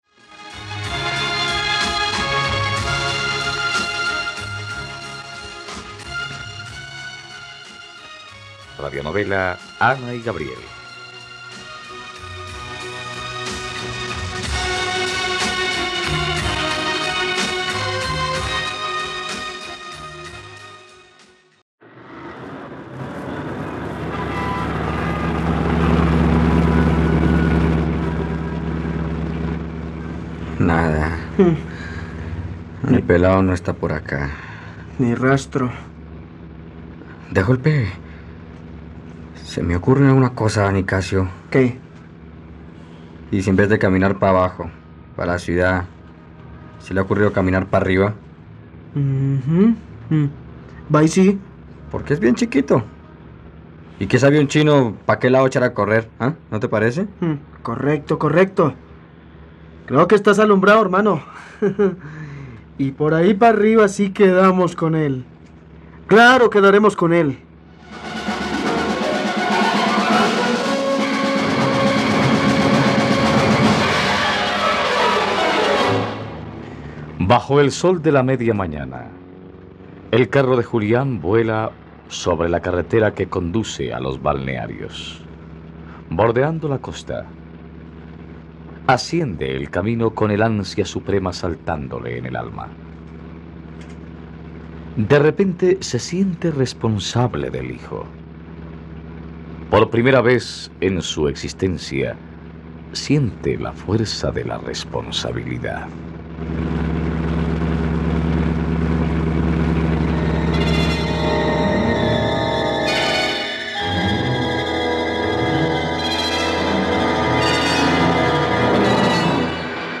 Ana y Gabriel - Radionovela, capítulo 89 | RTVCPlay